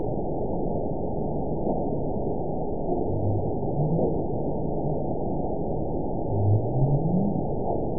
event 911818 date 03/09/22 time 14:32:13 GMT (3 years, 2 months ago) score 9.64 location TSS-AB01 detected by nrw target species NRW annotations +NRW Spectrogram: Frequency (kHz) vs. Time (s) audio not available .wav